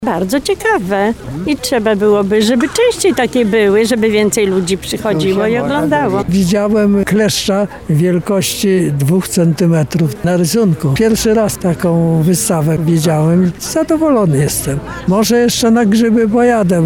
seniorzy
Starsi_Panstwo.mp3